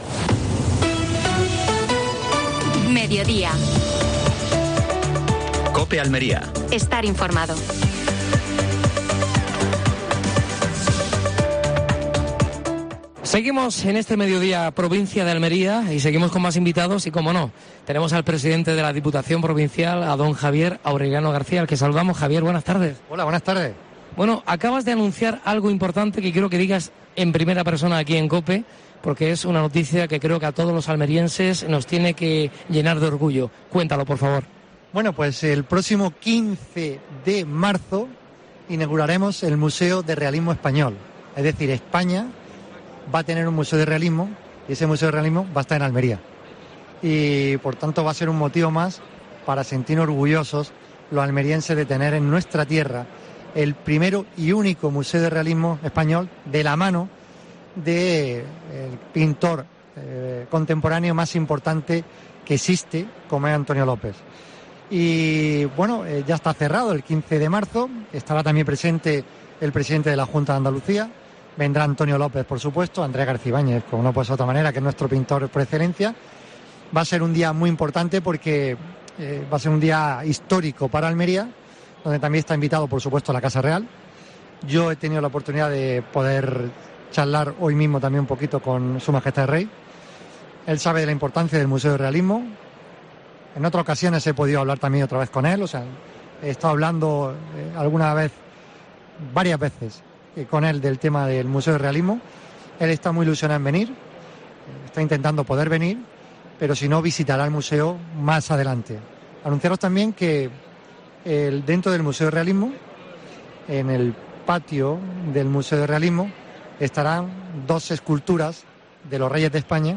Actualidad en Almería. Especial FITUR desde IFEMA (Madrid). Entrevista a Javier A. García (presidente de la Diputación Provincial de Almería).